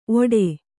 ♪ oḍe